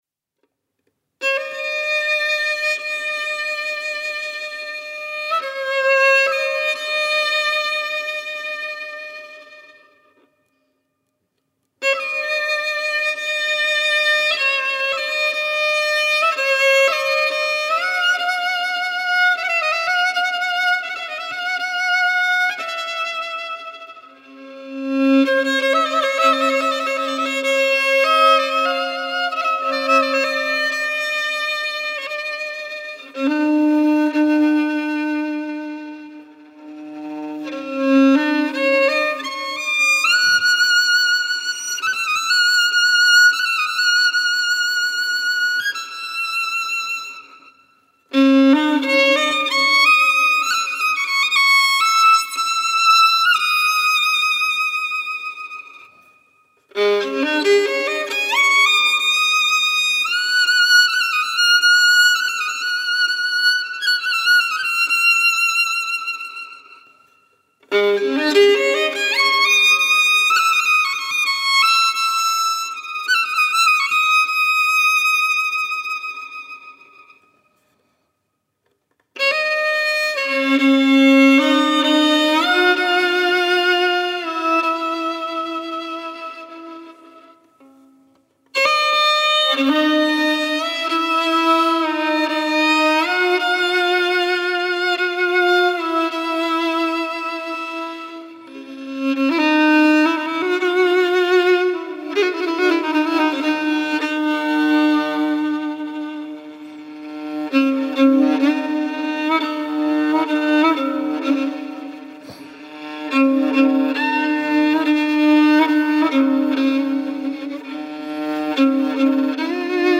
مجموعه مناجات های فارسی همراه با موسیقی